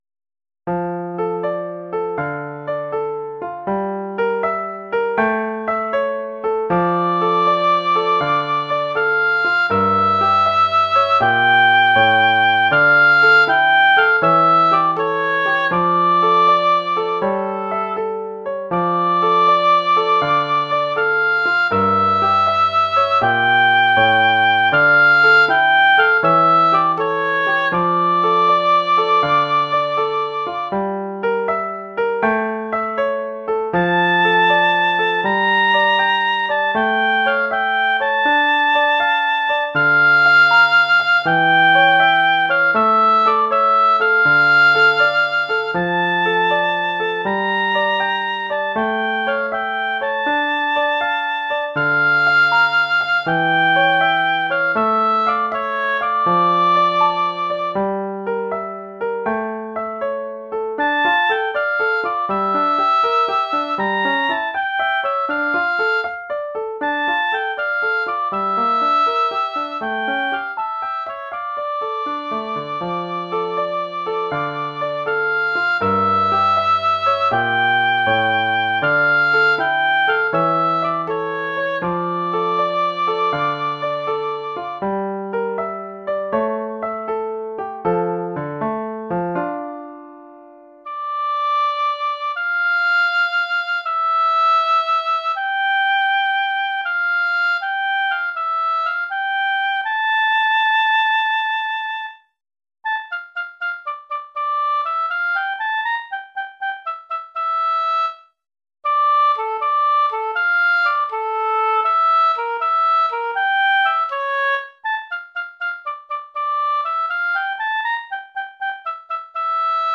Oeuvre pour hautbois et piano.